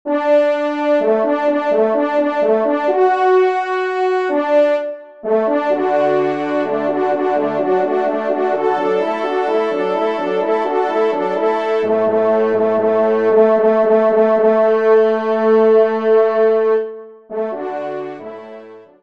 Genre : Musique Religieuse pour Trois Trompes ou Cors